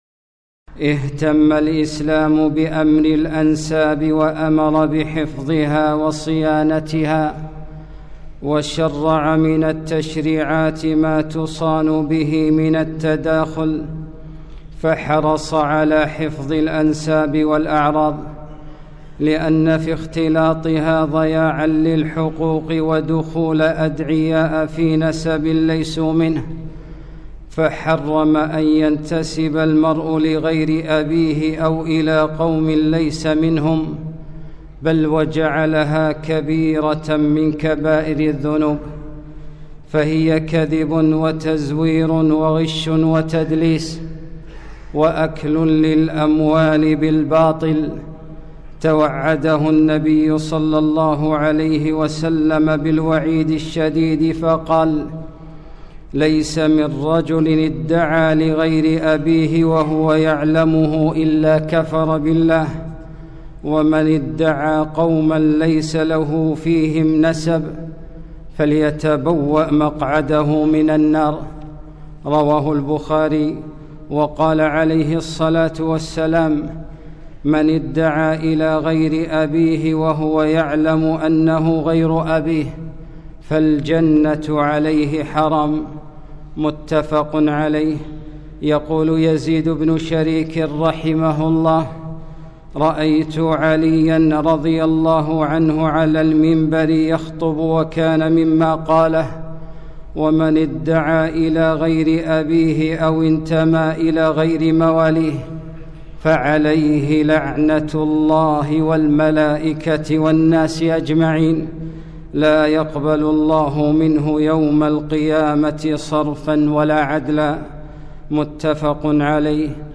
خطبة - ادعوهم لآبائهم